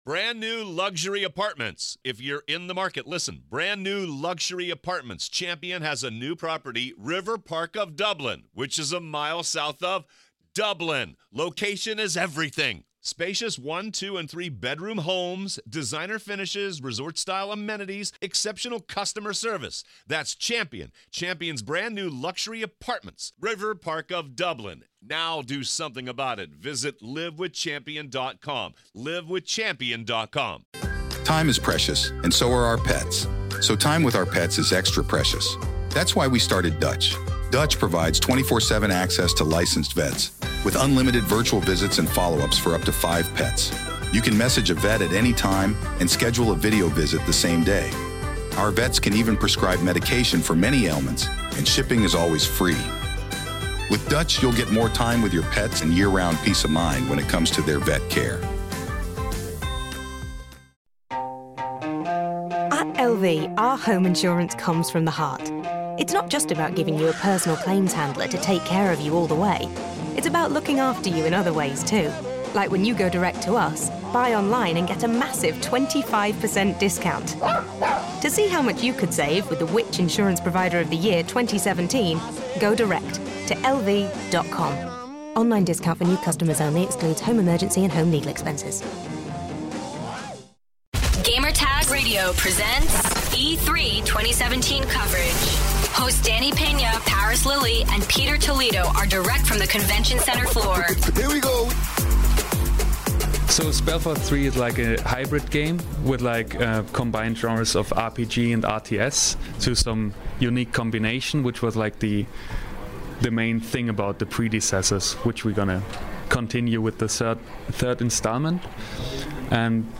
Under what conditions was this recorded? E3 2017: Spellforce 3 Presentation